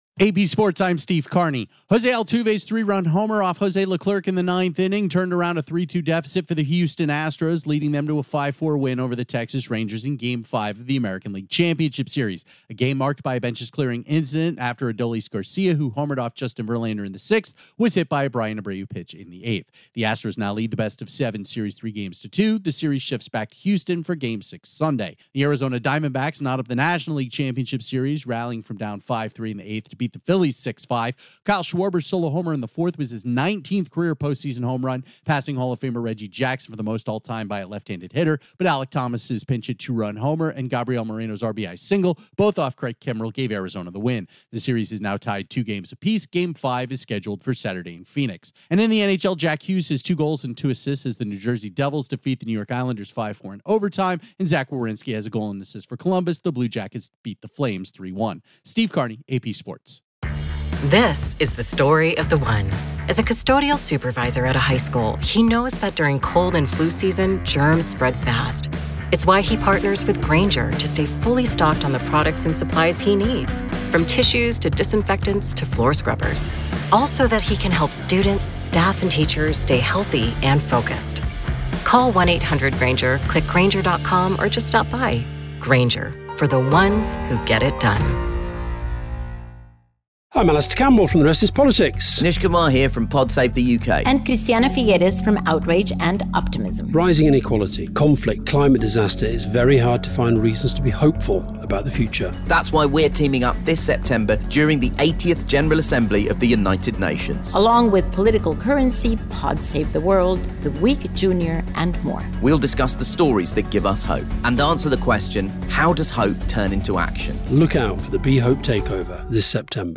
The Astros are a win away from reaching the World Series, the Diamondbacks even up their series with the Phillies, Jack Hughes has a four-point night for the Devils and Zach Werenski leads the Blue Jackets to victory. Correspondent